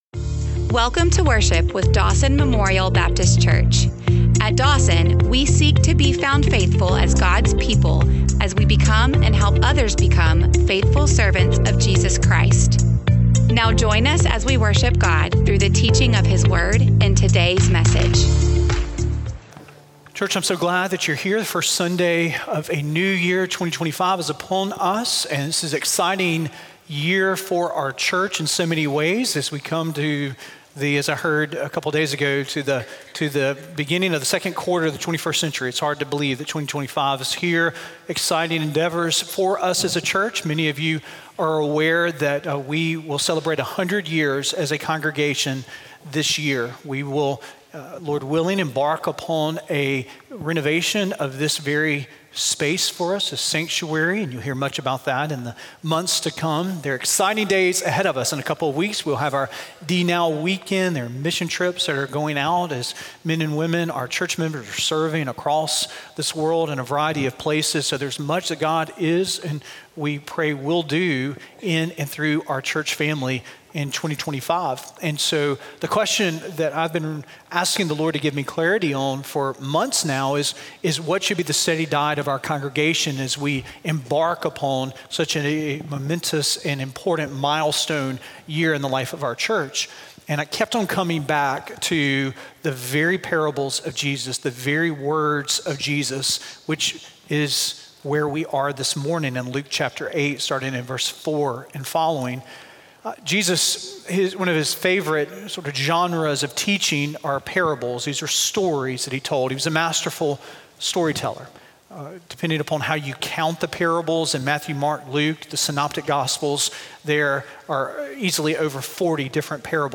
1525SermonAudio.mp3